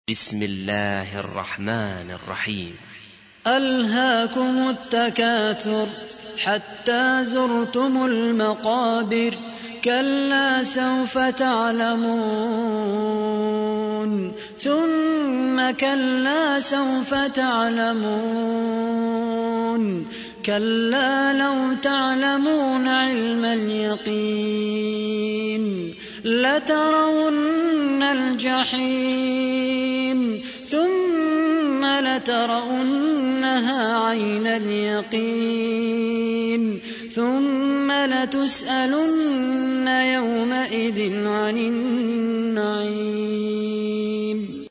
تحميل : 102. سورة التكاثر / القارئ خالد القحطاني / القرآن الكريم / موقع يا حسين